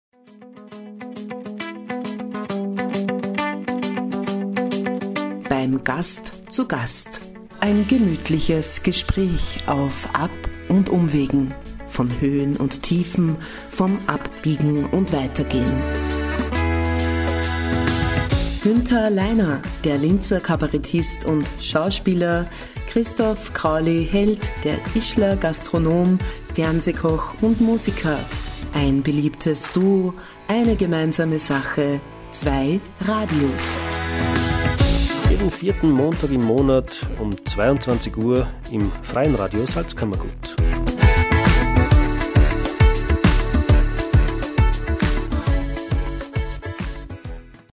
Sendungstrailer
Im Podgast „Beim Gast zu Gast“ wird gegessen, getrunken, gelacht und erzählt, manchmal Spannendes diskutiert und dann wieder ganz unaufgeregt und zwanglos wie zu Hause mit Freund:innen geplaudert.